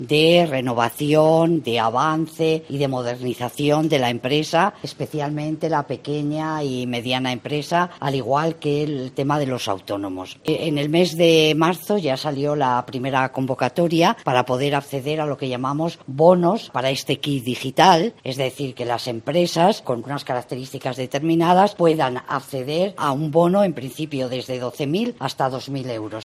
Encarnación Pérez, Subdelegada del Gobierno en Salamanca, indica de qué objetivo se trata y da algunas cifras